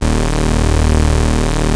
OSCAR 1  D#2.wav